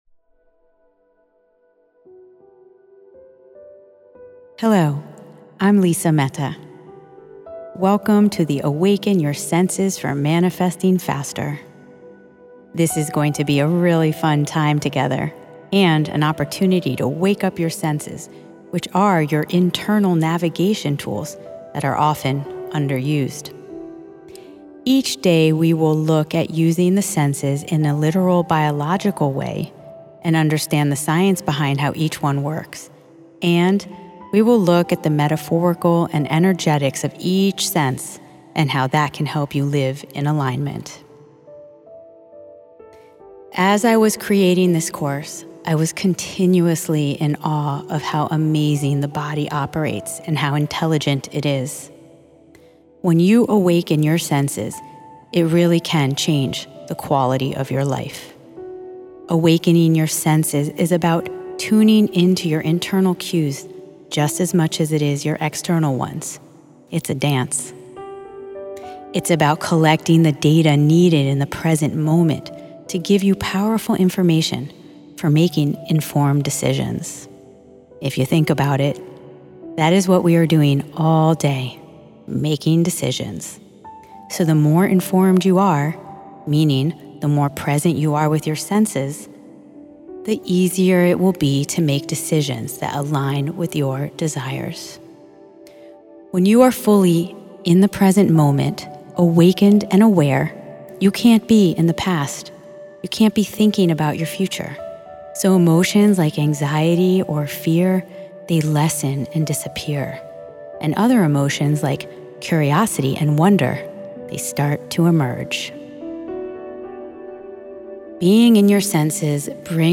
This audio course is a playful, powerful journey through your six senses – the five traditional senses plus your sense of intuition.